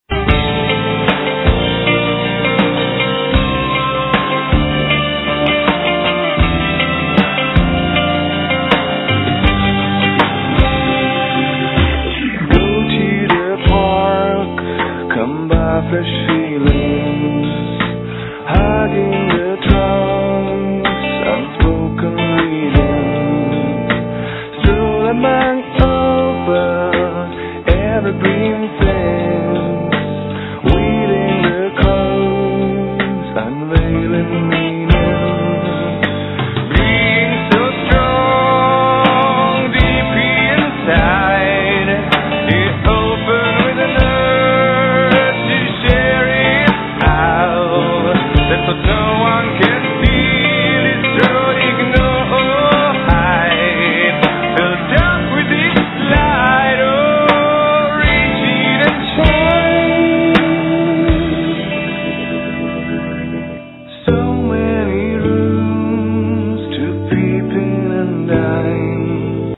El. & Ac. guitar, Back vocals.
El. guitar, Ac. & El. Violin
Bass, Double bass, Hammonds, Yamaha DX7, Percussions, Noise